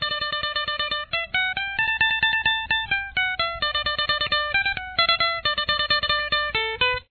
• Key Of: D
Guitar 3: (lead mandolin)